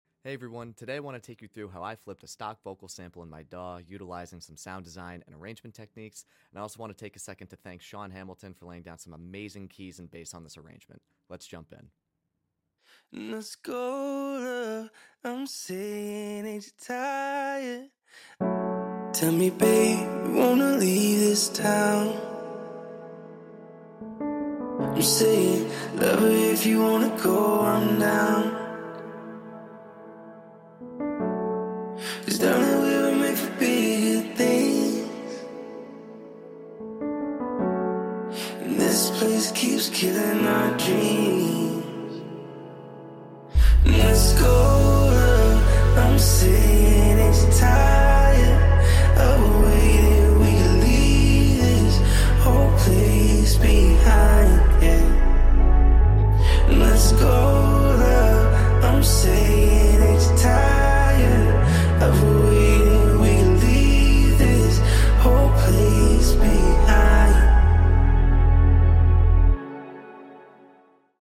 Flipped a stock vocal sample in my DAW
keys and bass